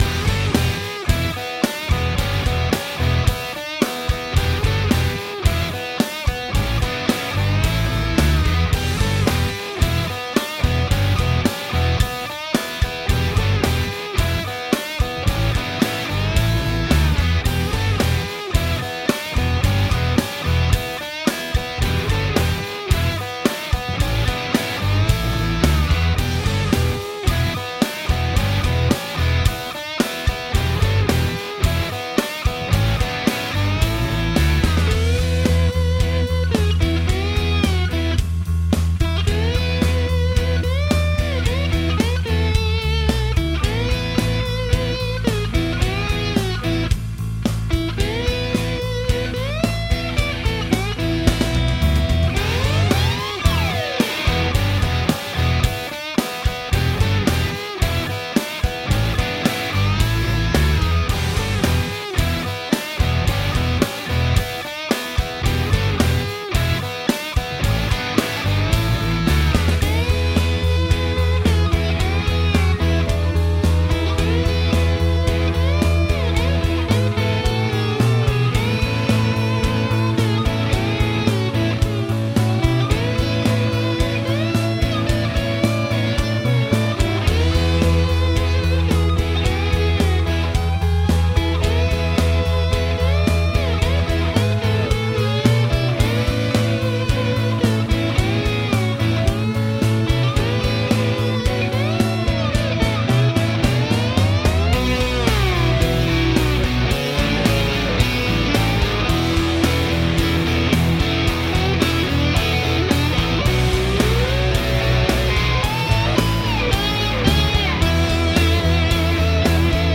Quality guitar driven instrumental music.